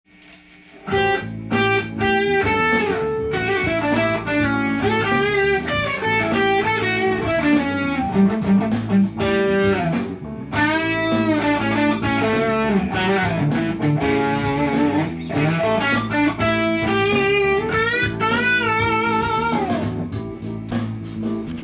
実演　アドリブソロtab
カラオケ音源にあわせて譜面通り弾いてみました
A7ワンコードになっていますので
ファンキーな雰囲気を作ることが可能です。